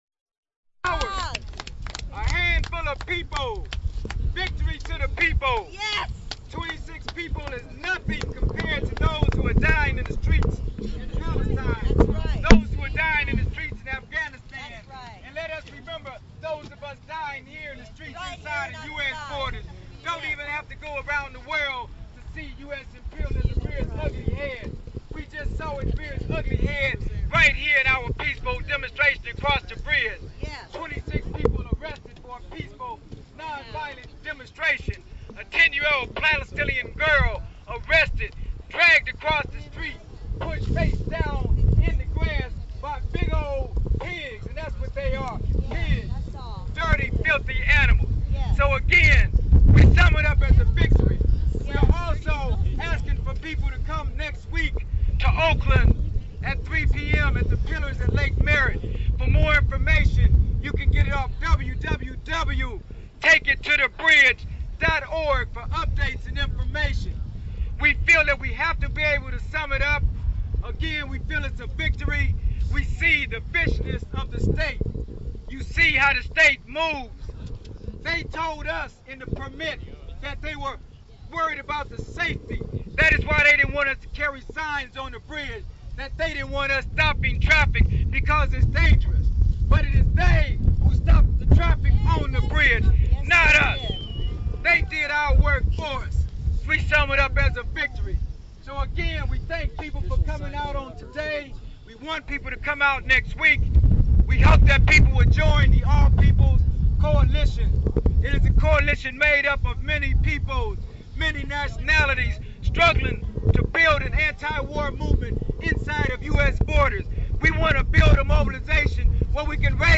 Wrap up speeches recorded after Take It To the Bridge.